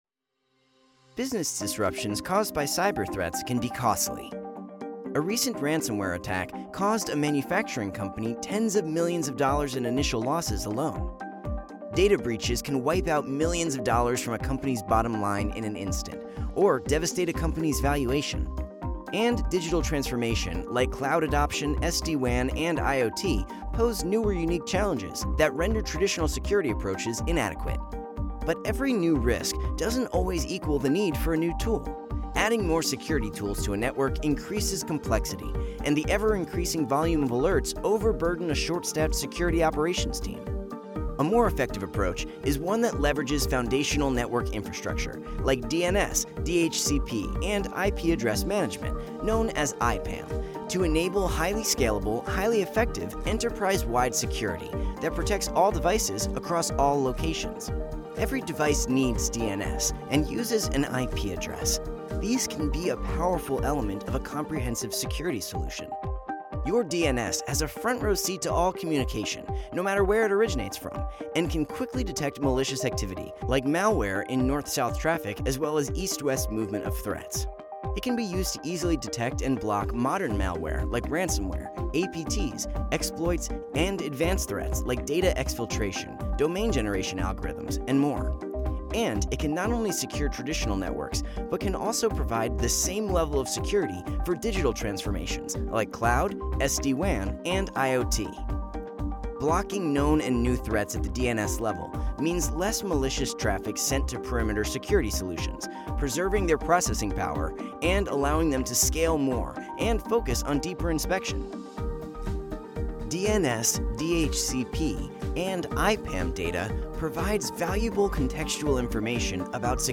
0728Business_Tech_Narration_Demo.mp3